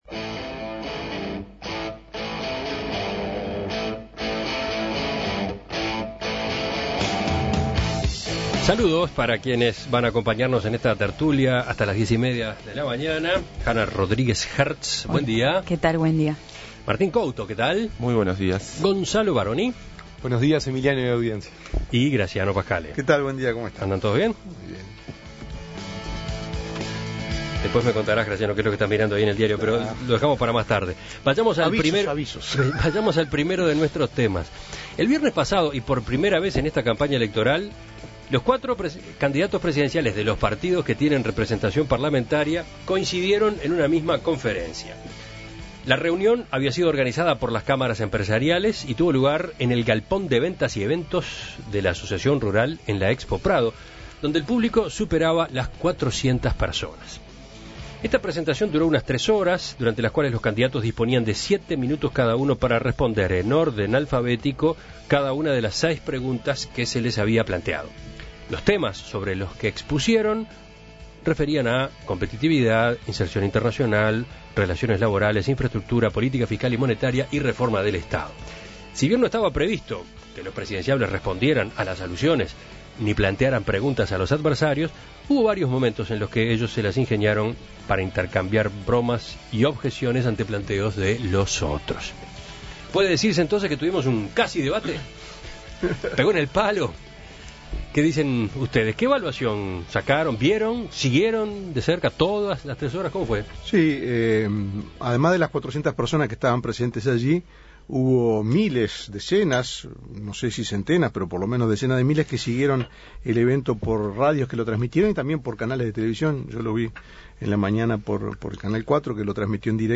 La reunión fue organizada por las Cámaras Empresariales y tuvo lugar el galpón de ventas y eventos de la Asociación Rural en la Expo Prado, donde el público superaba las 400 personas.